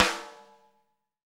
Index of /90_sSampleCDs/Roland - Rhythm Section/KIT_Drum Kits 7/KIT_Loose Kit